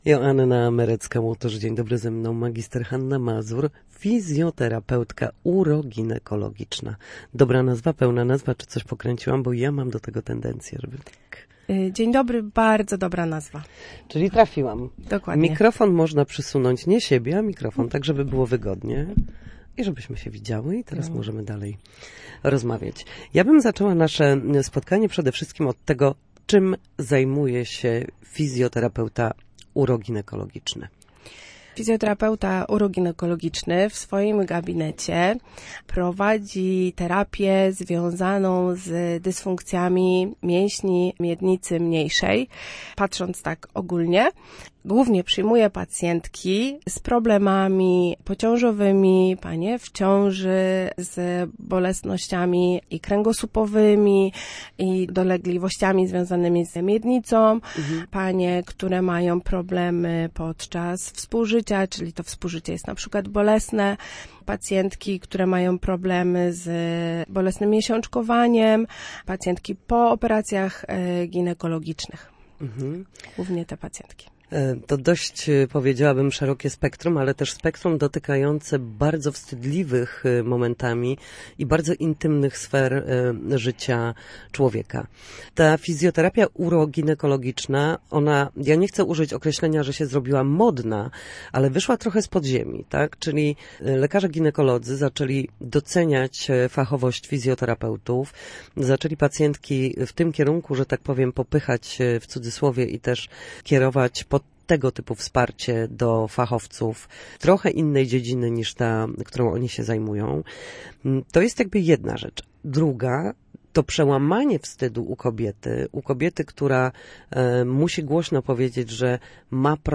W każdą środę, w popołudniowym Studiu Słupsk Radia Gdańsk, dyskutujemy o tym, jak wrócić do formy po chorobach i urazach.